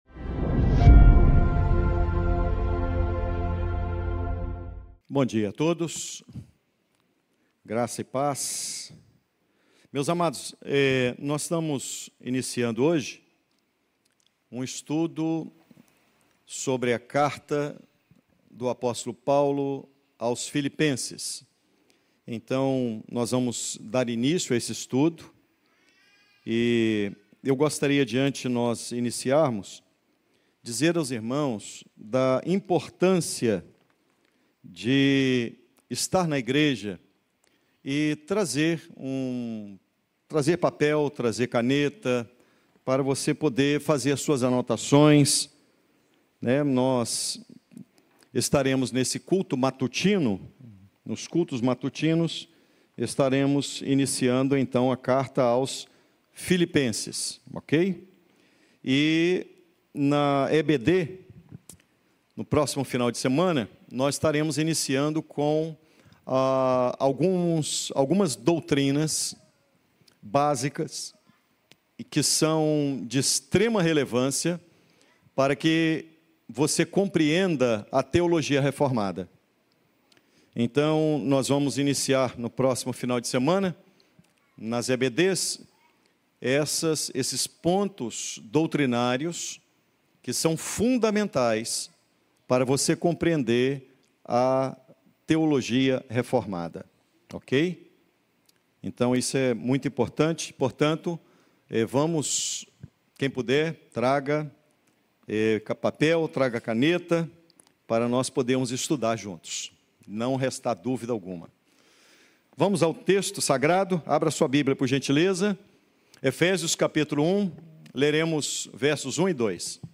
Escola Bíblica Dominical